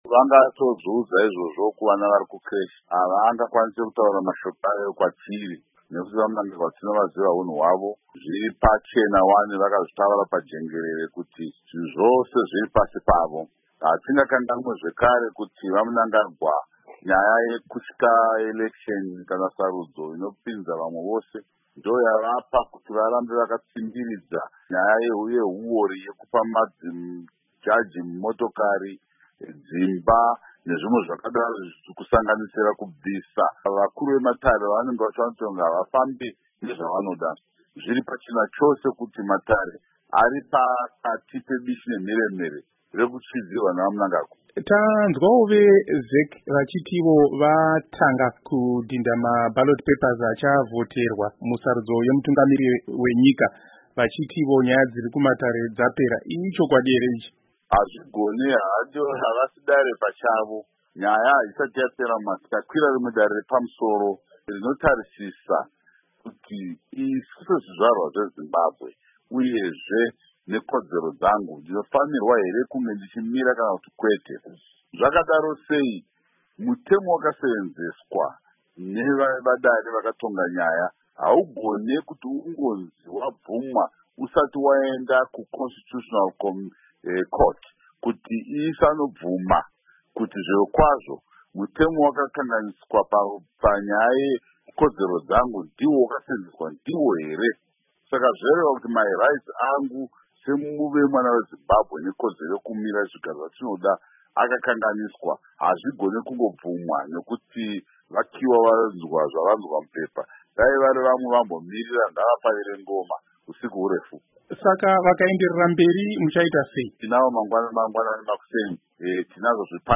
Hurukuro naVaSaviour Kasukuwere